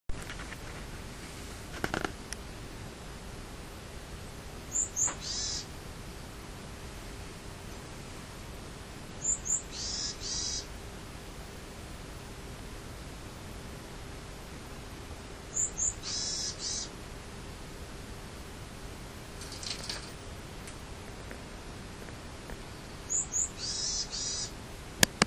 途中、ヤマガラが窓際までやってきて激励してくれました。